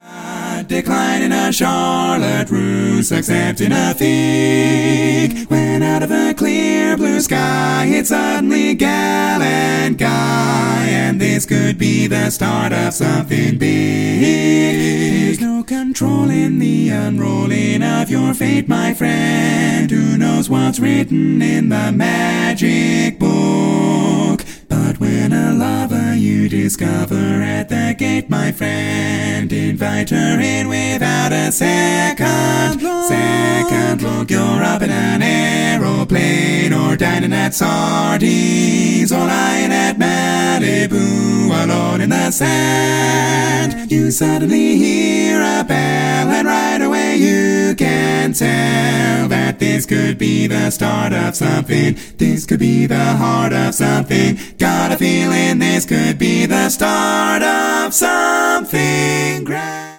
Male
Down a tone (Eflat), amended tag